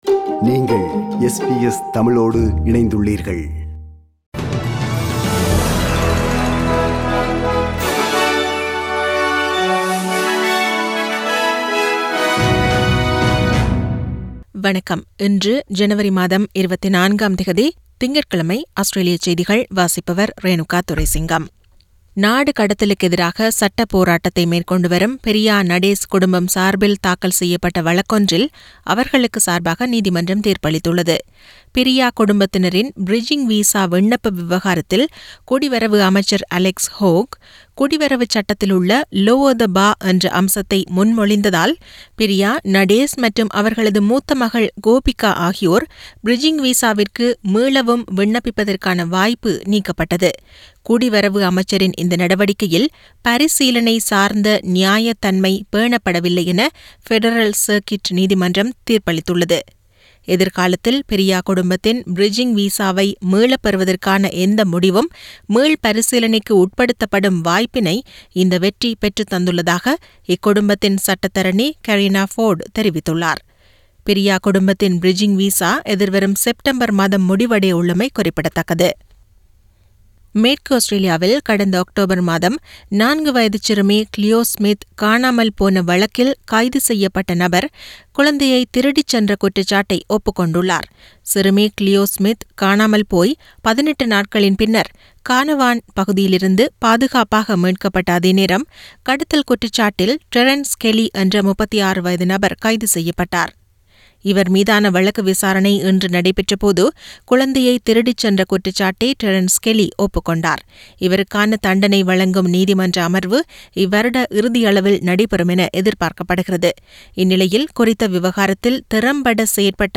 Australian news bulletin for Monday 24 Jan 2022.